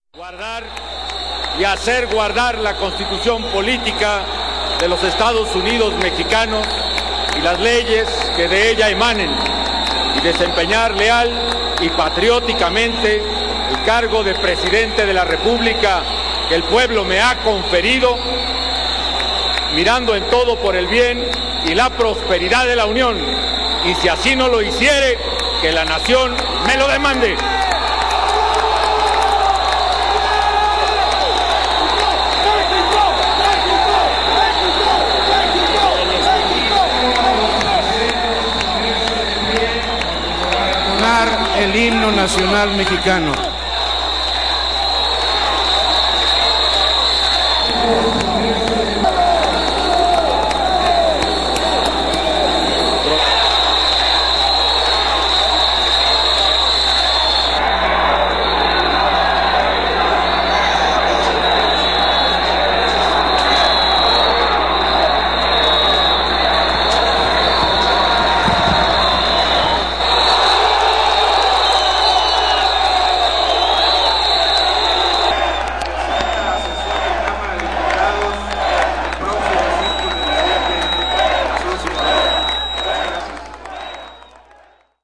Independiente al debate político, publicamos este registro ya que utilizaron el sonido como instrumento para obstaculizar la toma de posesión de Felipe Calderón como presidente de la República Mexicana.
El uso masivo de silbatos fue una idea estudiada y bien calculada para su ejecución.
Fuente del sonido: Canal del Congreso de la Nación.